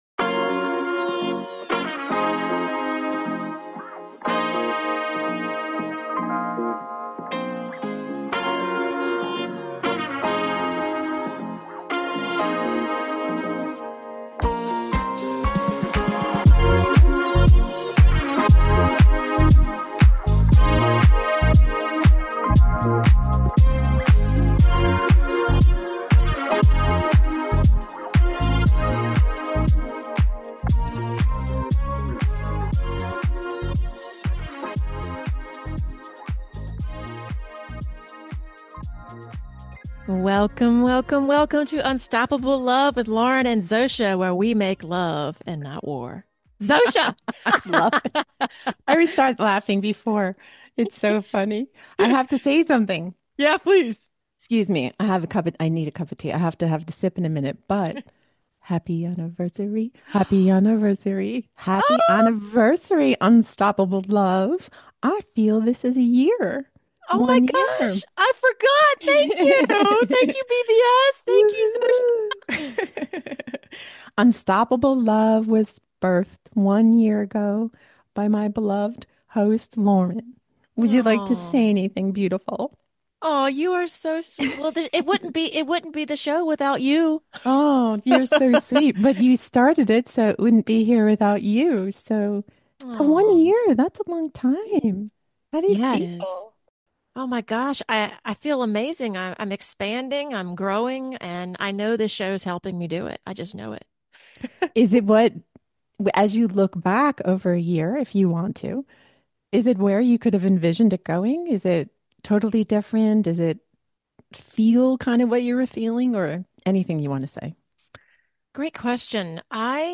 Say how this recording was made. Together and with the listeners, we are excited to create a coffee chat style atmosphere where we can all learn and evolve together-one laugh and smile at a time.